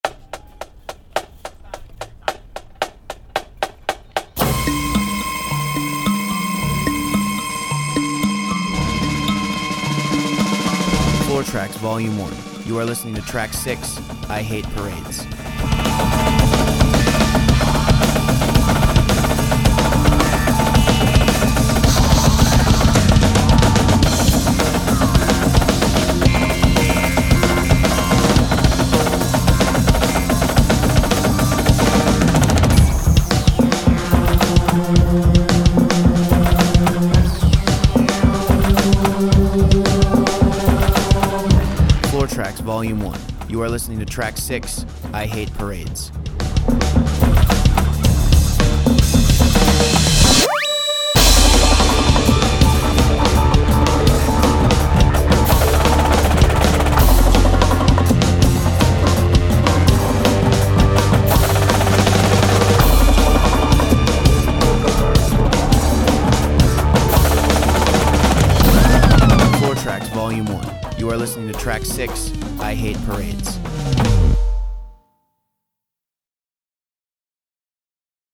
(with voiceover)   Purchase high-quality track